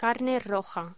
Locución: Carne roja
voz
Sonidos: Alimentación